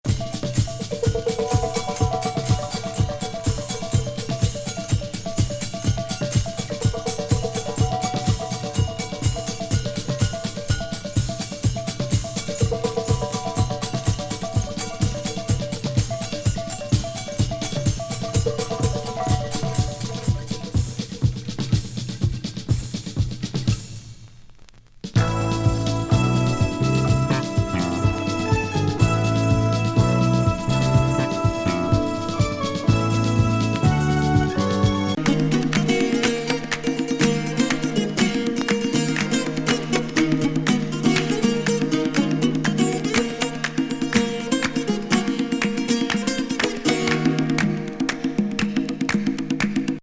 パーカッシブ・イントロ